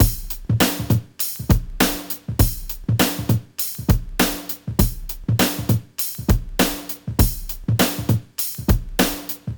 • 100.1 bpm G# ragga drumloop.wav
Ragga drumloop, I can imagine this would work great in a hiphop mix also.
100.1_bpm_G_sharp__ragga_drumloop_WvH.wav